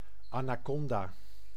Ääntäminen
IPA: [ɛ̃n‿a.na.kɔ̃.da]